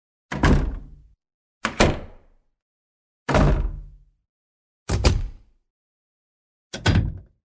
На этой странице собраны реалистичные звуки ручки двери: скрипы, щелчки, плавные и резкие повороты.
Закрытие двери — сборник